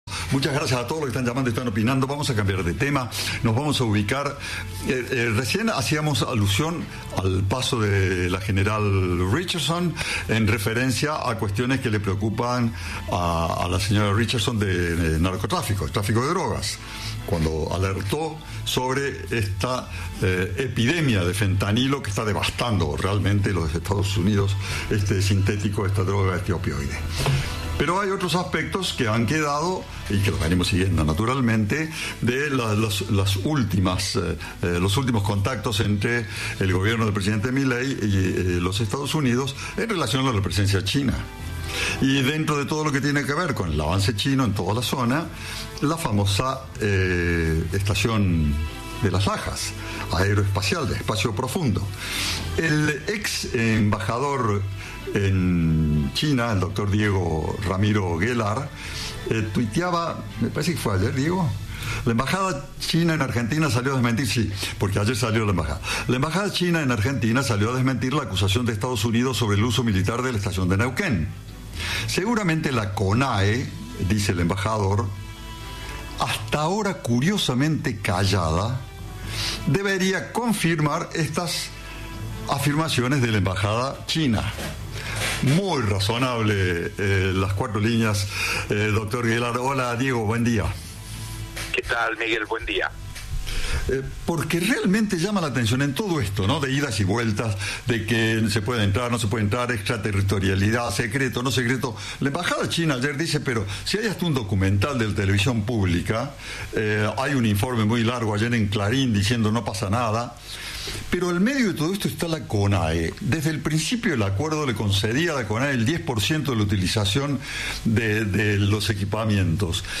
Lo hizo a través de su cuenta X. El exembajador Diego Guelar sugirió en diálogo con Cadena 3 que la Conae debería expresarse al respecto.
Entrevista